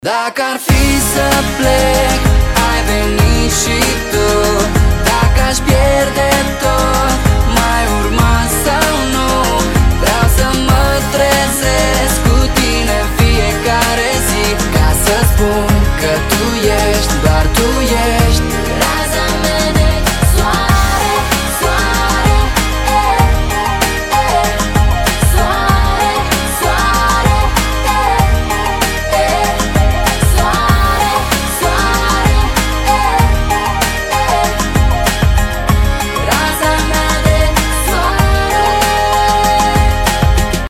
Румынская или Хорватская попса